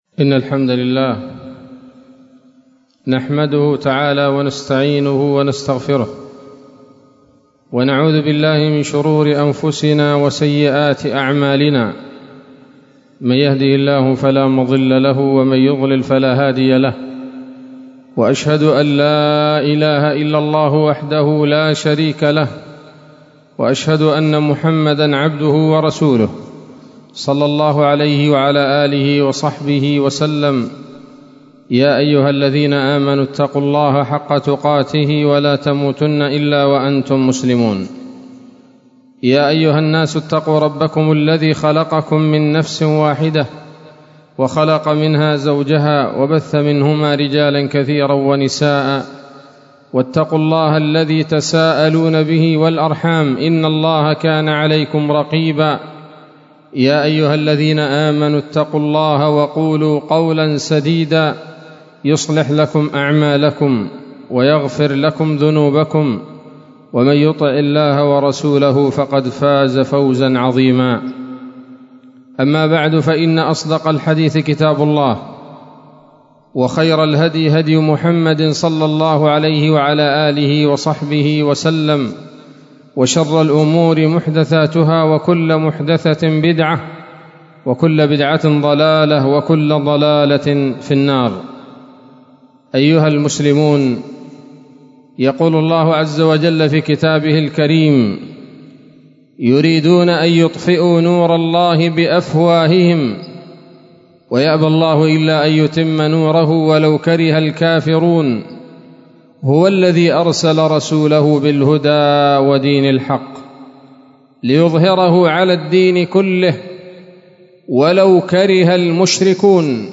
خطبة جمعة بعنوان: (( المستقبل لدين الإسلام )) 19 شوال 1443 هـ، دار الحديث السلفية بصلاح الدين